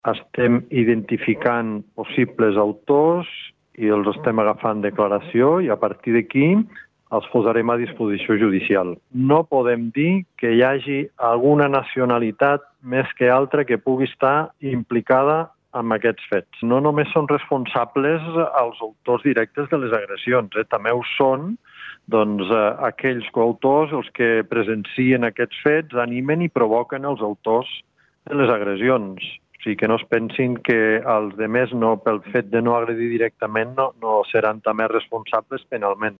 Així mateix, Bruno Lasne, director del Cos de Policia, ha incidit en declaracions a Ràdio Nacional que, no només els agressors poden tenir responsabilitat penal, sinó també aquells joves que animen i provoquen als que agredeixen.